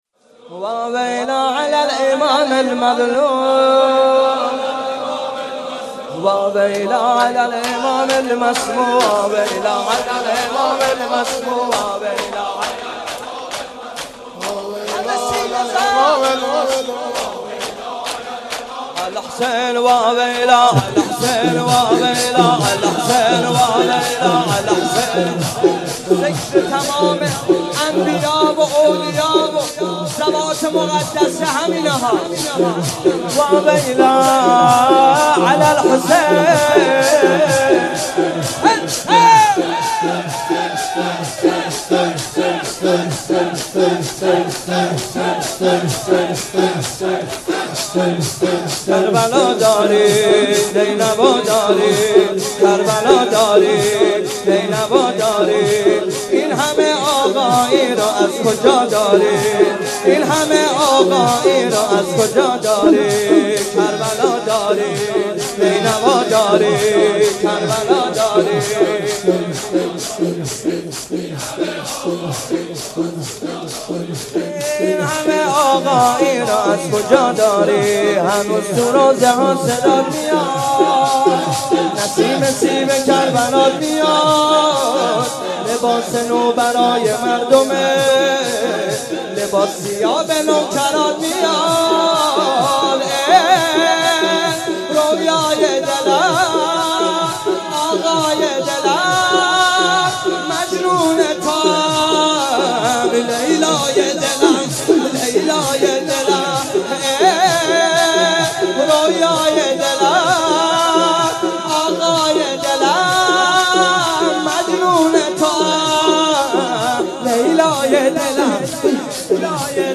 شب هفتم رمضان95، حاج محمدرضا طاهری